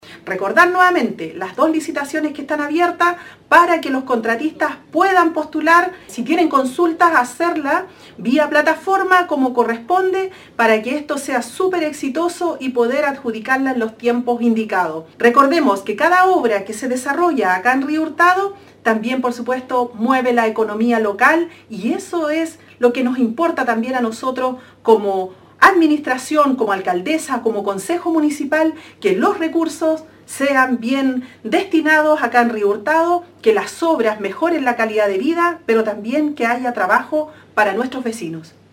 La alcaldesa de Río Hurtado Carmen Juana Olivares comenta más sobre estas dos iniciativas para la comuna.